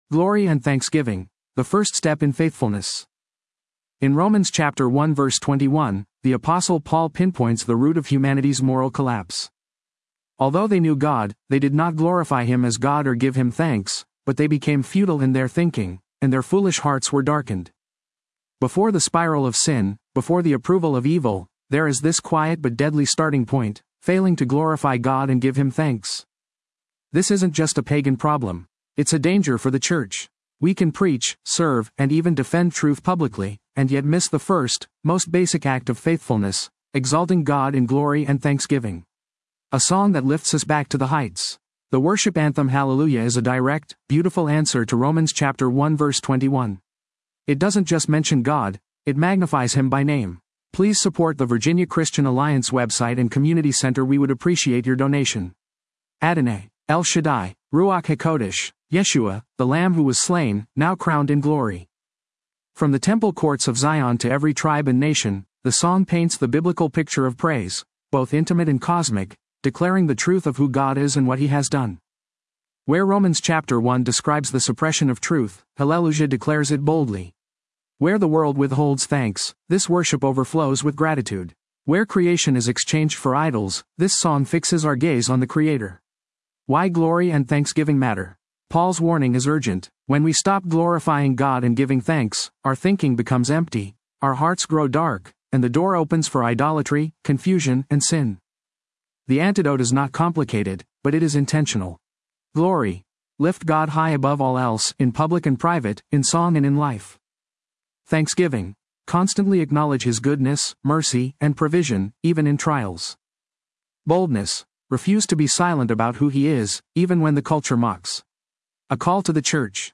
The worship anthem Hallelujah is a direct, beautiful answer to Romans 1:21.